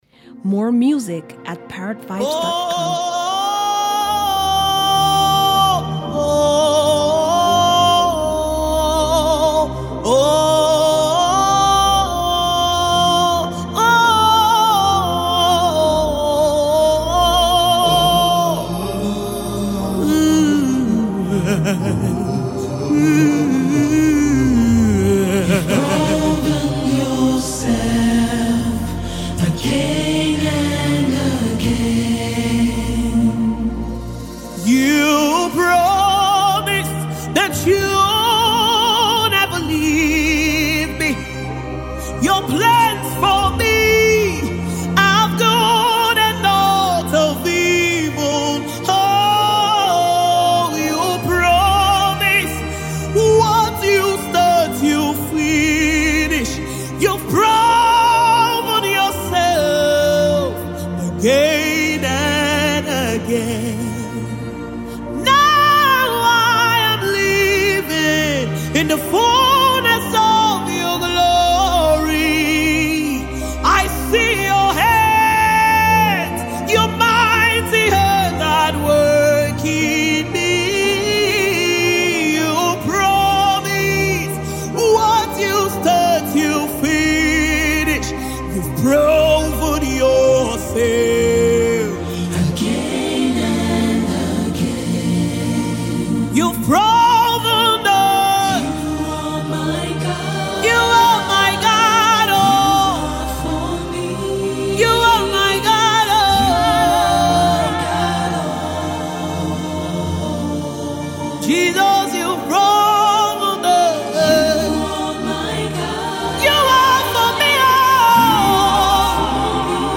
soul-lifting song
GOSPEL